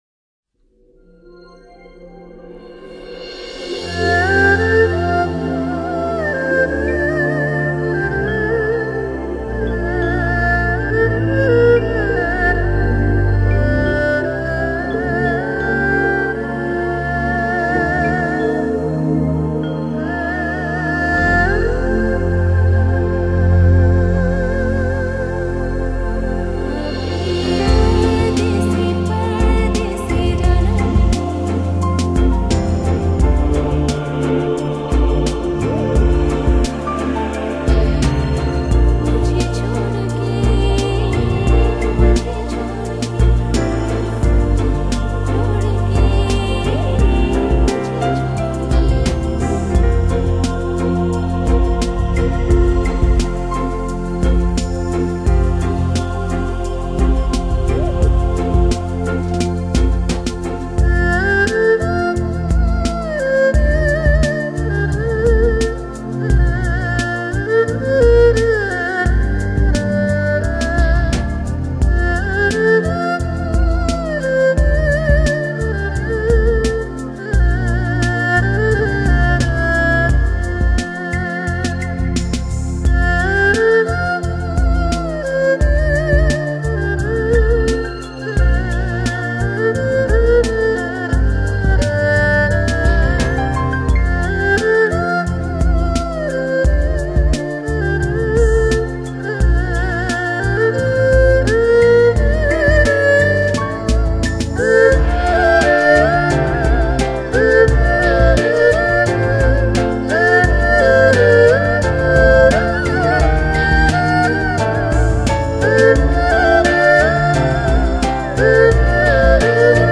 细腻清悠的二胡
音色如歌声般细腻，那种清清的哀愁，